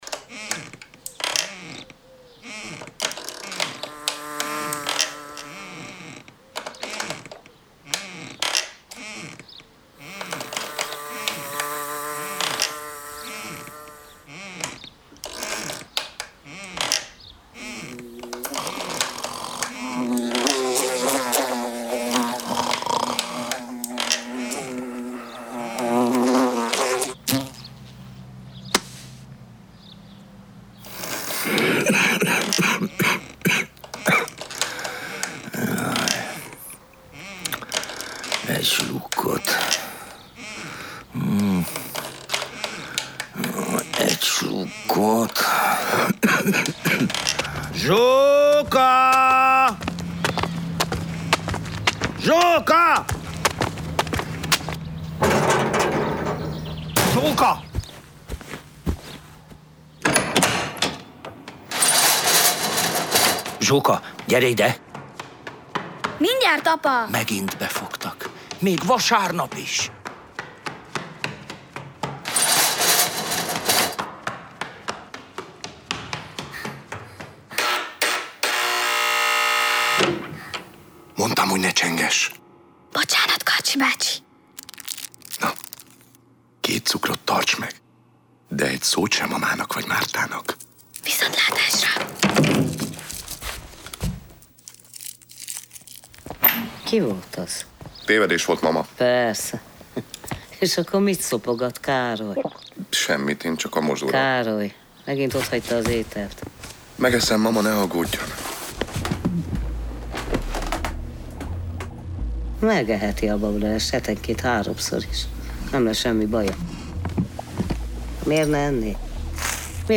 C lépcsőház – hangjáték a Rádiószínpadon
Bálint Tibor Angyaljárás a lépcsőházban című rövidprózakötetének több novellájából készült rádiójátékot hallják.